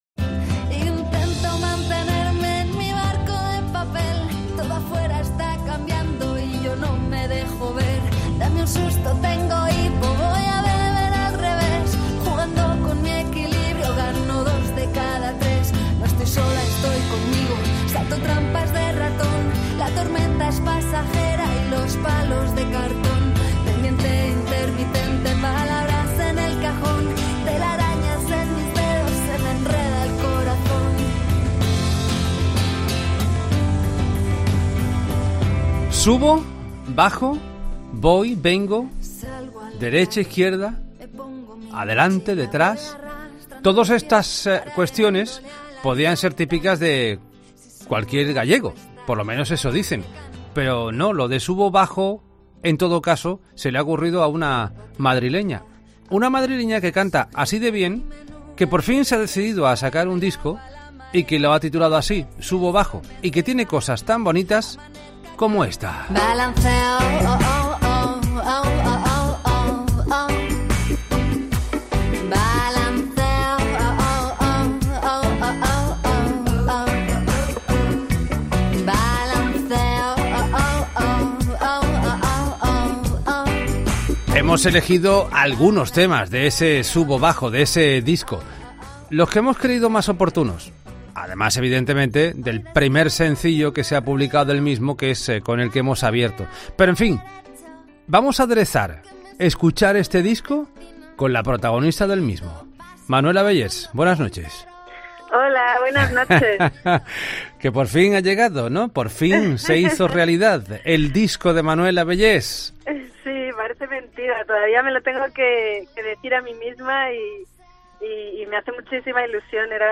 'La Noche de COPE' charla con la actriz y cantante madrileña